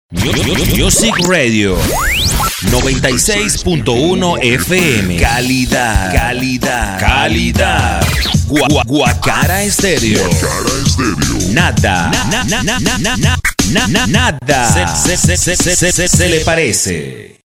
kolumbianisch
Sprechprobe: eLearning (Muttersprache):